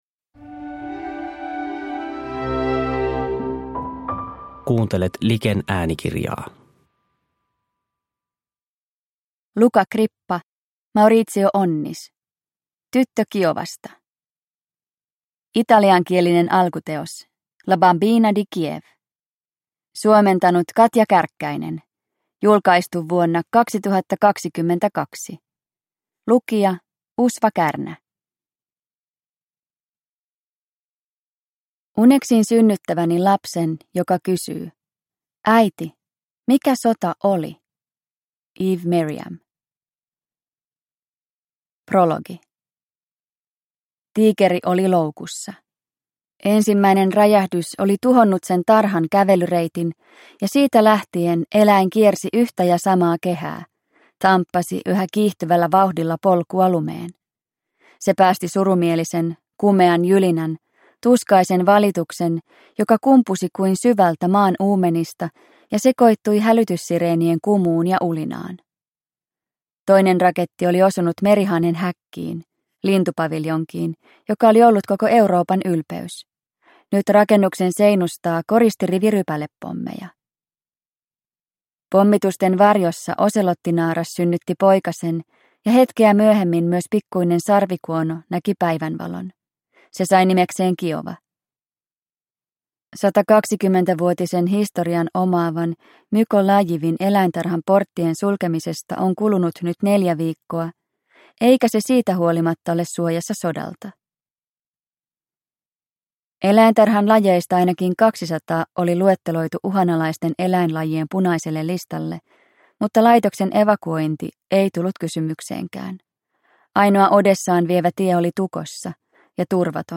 Tyttö Kiovasta – Ljudbok – Laddas ner